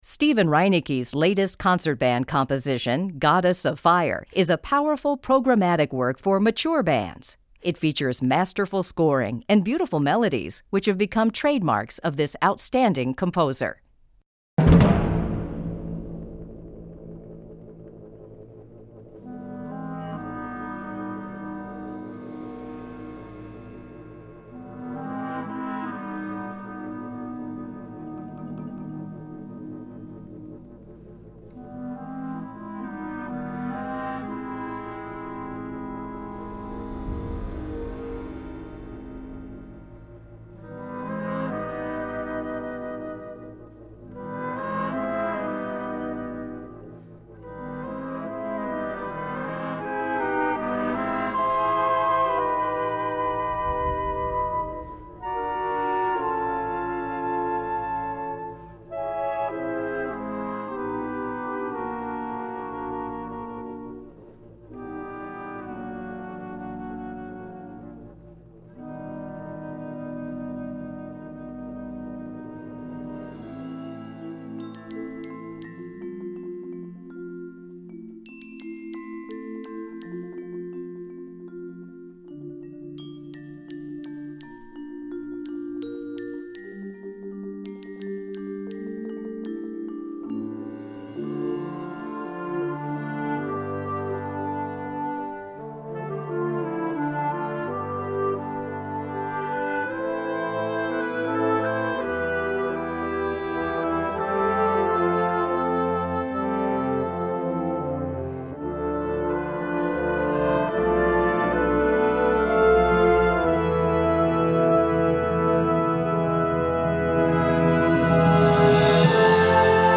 Gattung: Konzertwerk
Besetzung: Blasorchester
Packend, dramatisch, abwechslungsreich